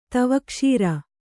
♪ tavakṣīra